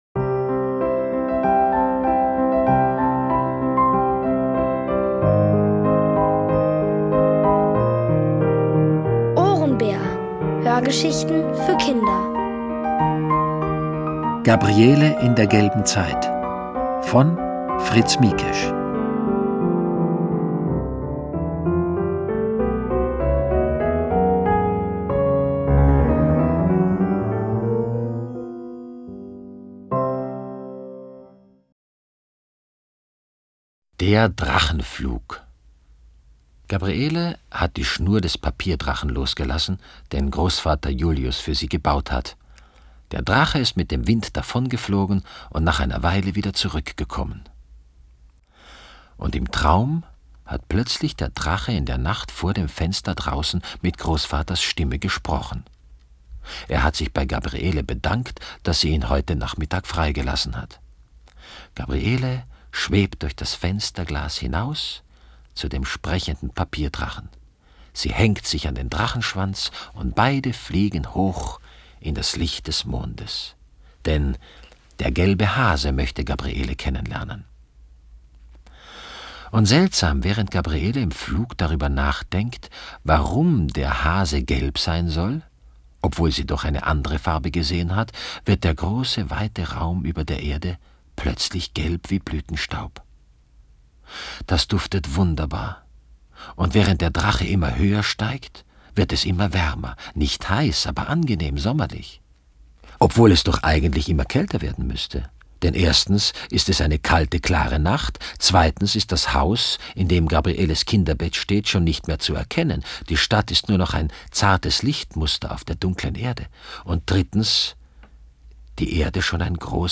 Von Autoren extra für die Reihe geschrieben und von bekannten Schauspielern gelesen.
OHRENBÄR-Hörgeschichte: Gabriele in der gelben Zeit (Folge 3 von 7)
Es liest: Peter Simonischek.